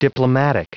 Prononciation du mot diplomatic en anglais (fichier audio)
Prononciation du mot : diplomatic